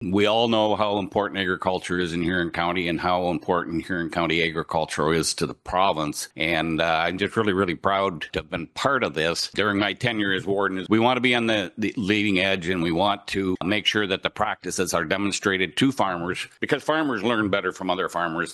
Central Huron Mayor, Jim Ginn addressed the council once the presentation was complete.